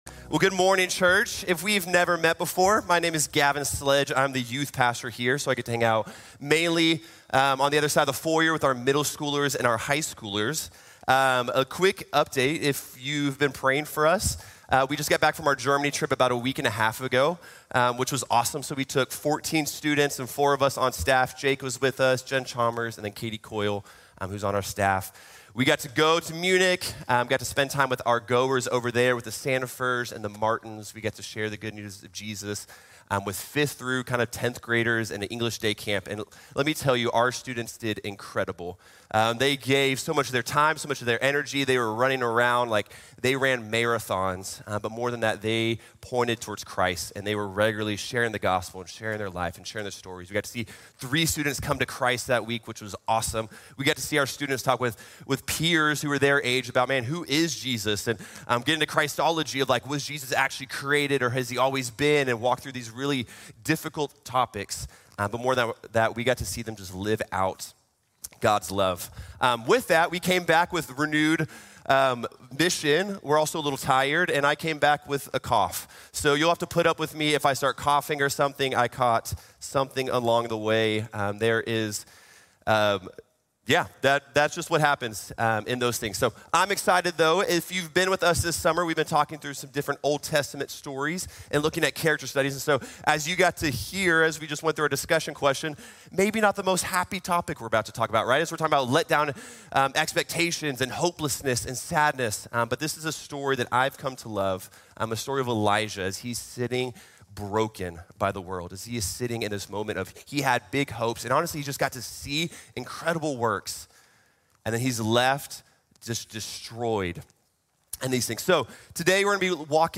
When All Hope Seems Lost | Sermon | Grace Bible Church